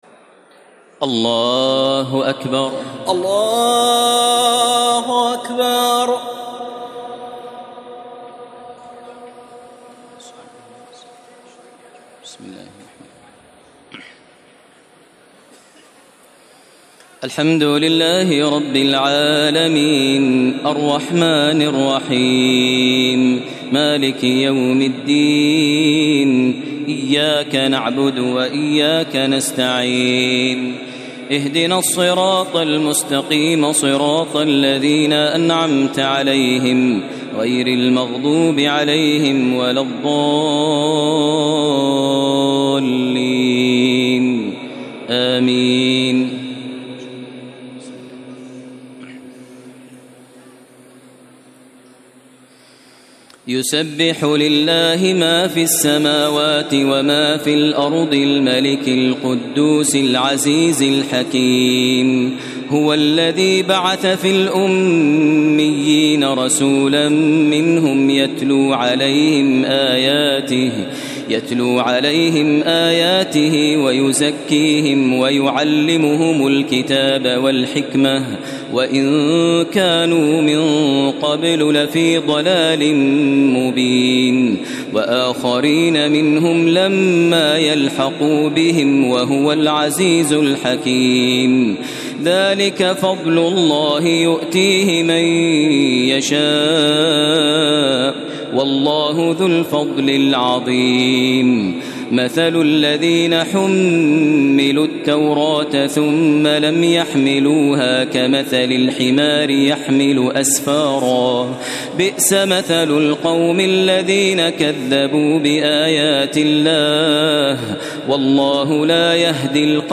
تراويح ليلة 27 رمضان 1432هـ من سورة الجمعة الى التحريم Taraweeh 27 st night Ramadan 1432H from Surah Al-Jumu'a to At-Tahrim > تراويح الحرم المكي عام 1432 🕋 > التراويح - تلاوات الحرمين